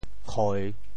契 部首拼音 部首 大 总笔划 9 部外笔划 6 普通话 qì qiè xiè 潮州发音 潮州 koi3 kiêg4 siêg4 潮阳 koi3 kiag4 siag4 澄海 koi3 kiag4 siag4 揭阳 koi3 kiag4 siag4 饶平 koi3 kiag4 siag4 汕头 koi3 kiag4 siag4 中文解释 契〈名〉 后时圣人易之以书契。
khoi3.mp3